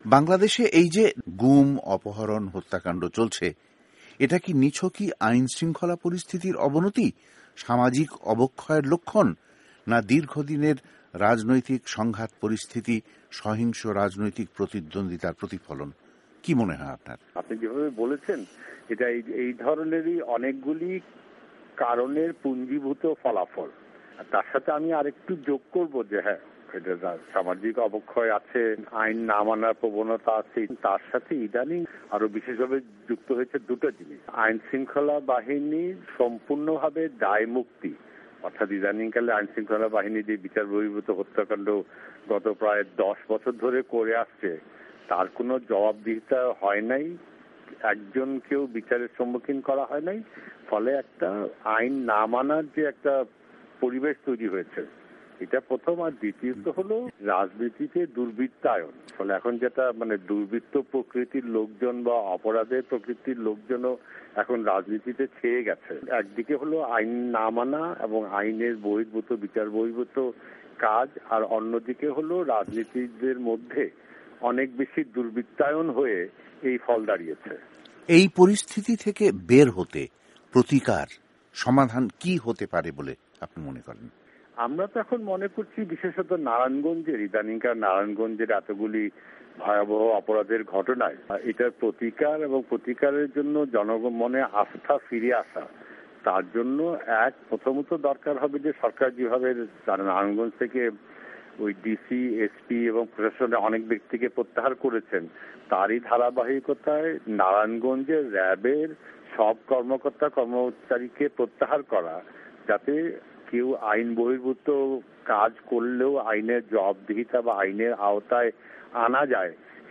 সাক্ষাত্কার